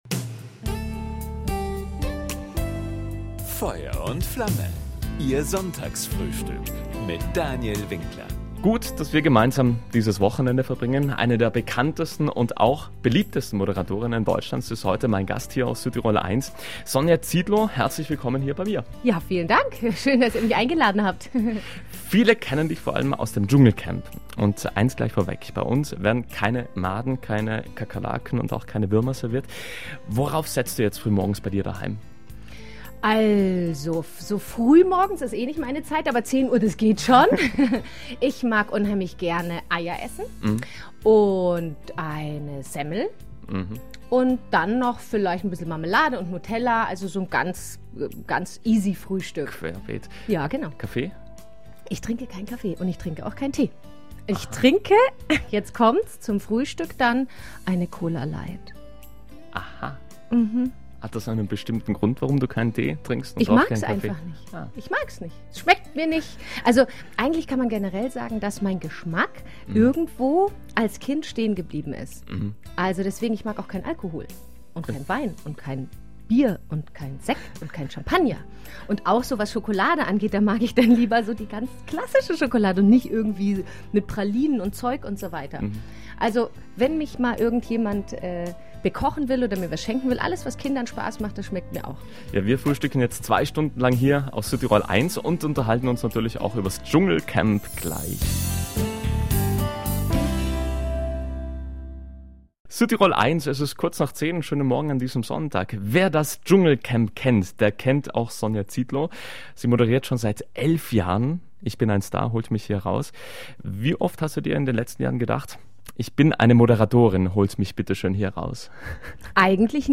Beim Sonntagsfrühstück auf Südtirol 1 erzählte die Moderatorin nicht nur von ihrem Leben mit Promistatus und netten, aber auch aufdringlichen Fans, sondern auch davon, was für sie Schönheit bedeutet und, wieviel Kind sein noch immer in ihr steckt.